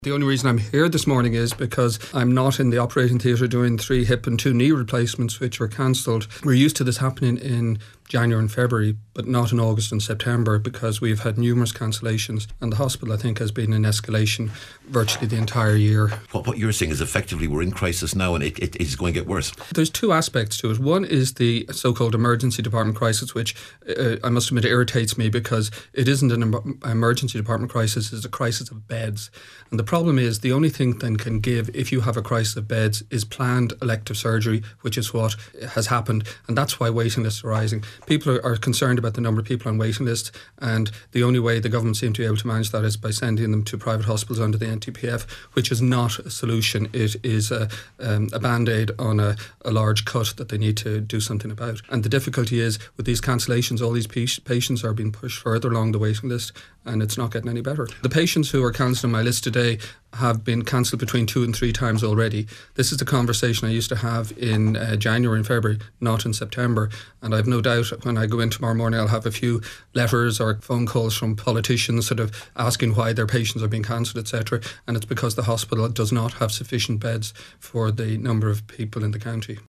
was speaking on today’s Nine ’til Noon Show…………